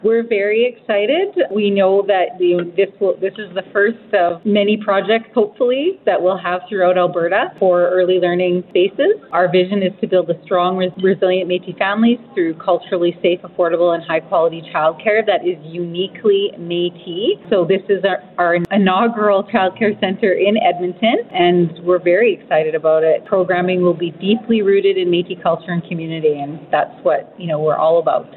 Andrea Sandmaier, President of the Otipemisiwak Métis Government speaks with CFWE on the excitement of the construction of the Child Care Centre, with the Métis culturally driven space to be affordable for Métis families.